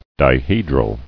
[di·he·dral]